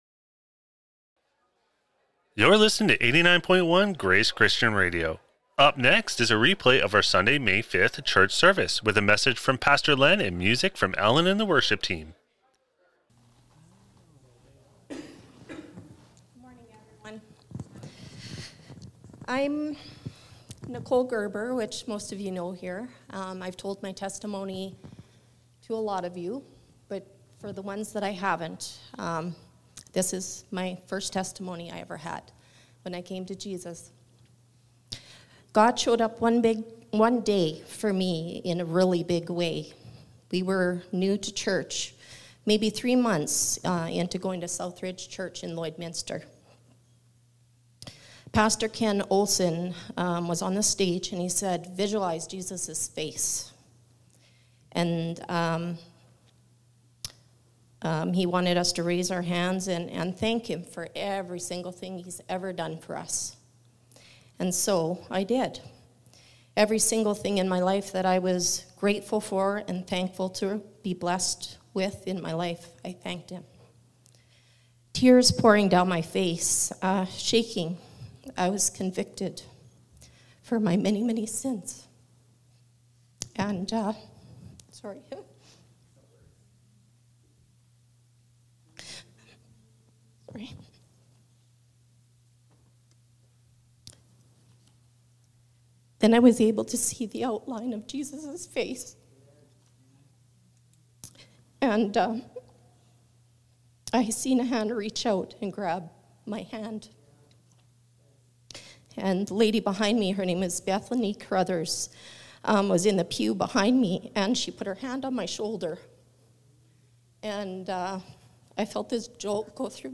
Sermons | Grace Christian Fellowship